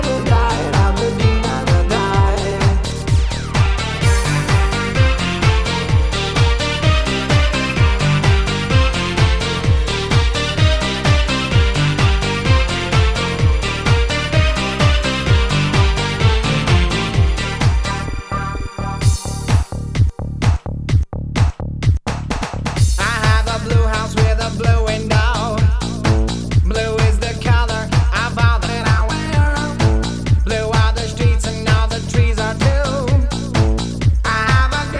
Dance Pop Britpop Rock
Жанр: Поп музыка / Рок / Танцевальные